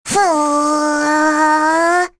Rehartna-Vox_Casting2.wav